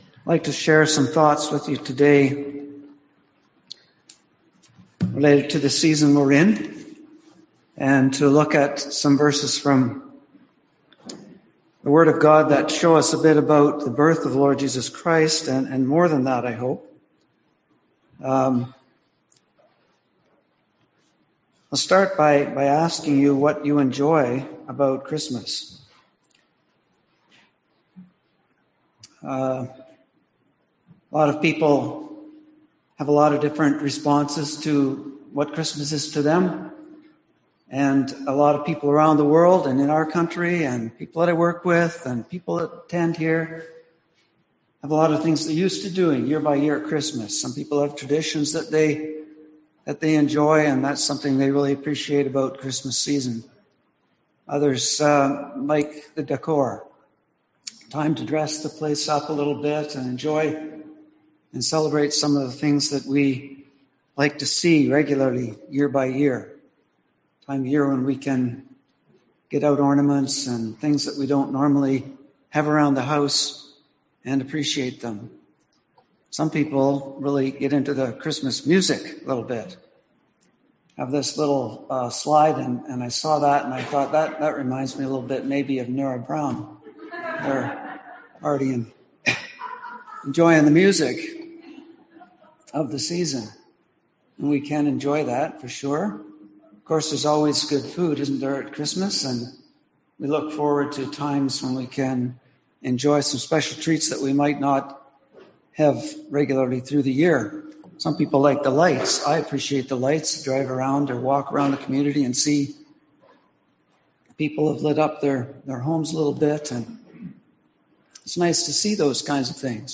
Passage: Matt 1-2, Luke 1-2 Service Type: Sunday AM